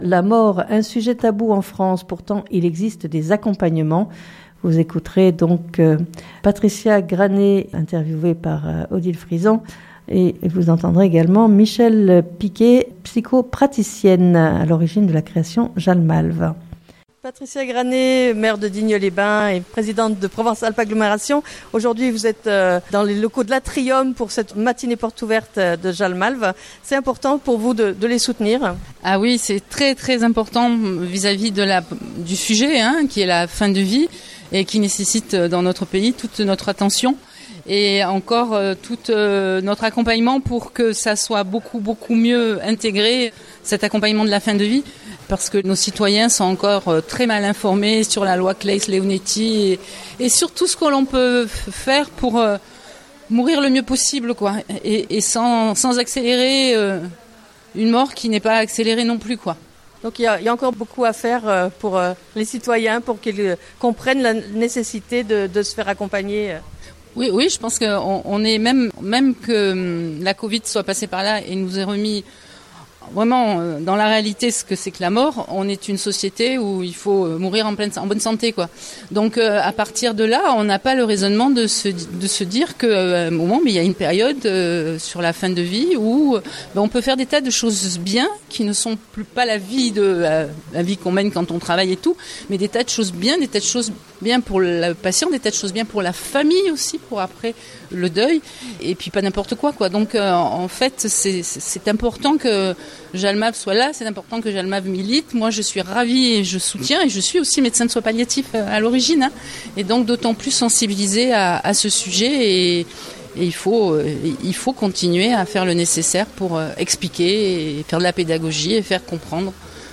Je vous propose d'écouter Patricia Granet-Brunello Maire de Digne les Bains, toujours présente lors des manifestations de JALMALV et fidèle soutien pour la fédération depuis longtemps.
Reportage